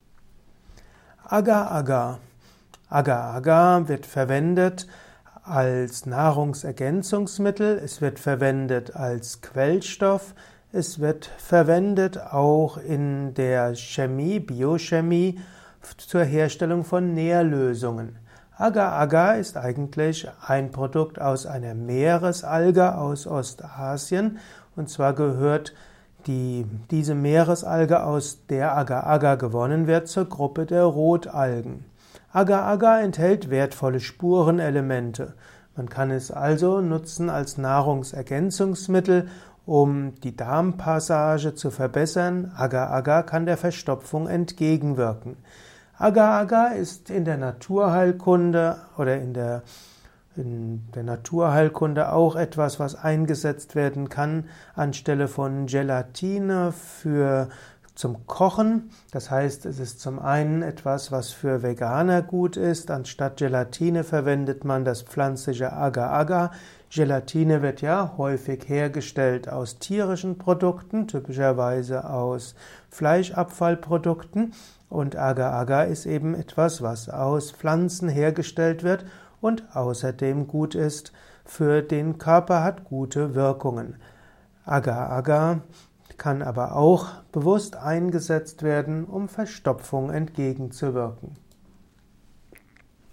Agar-Agar - Simple und komplexe Gedankengänge zum Thema Agar-Agar in diesem Kurzvortrag. Lausche einigen Ausführungen zu Agar-Agar vom Standpunkt der Yogatherapie aus.